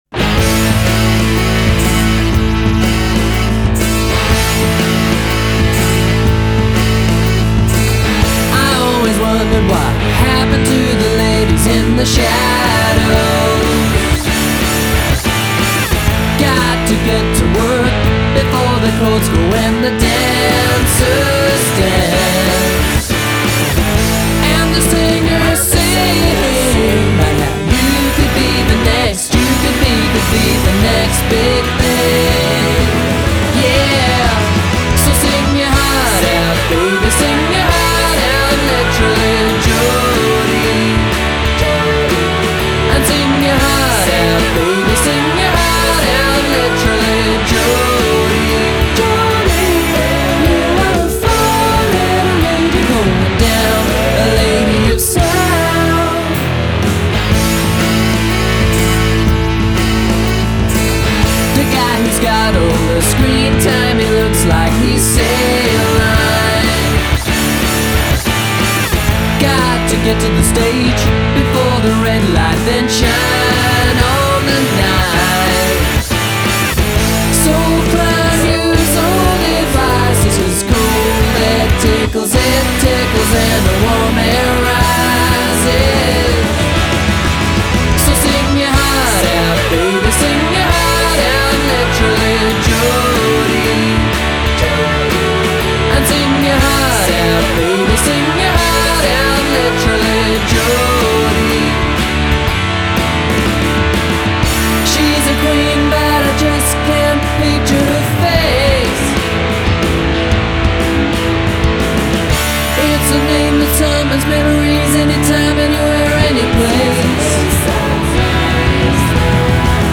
hook-laden